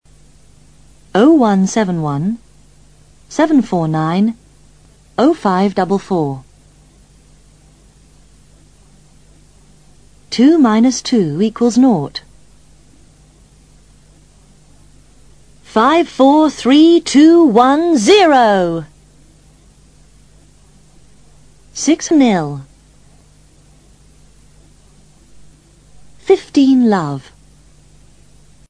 Pronunciación de números y símbolos (III)